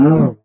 animalia_cow_hurt.ogg